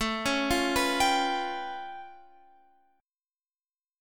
A Augmented 9th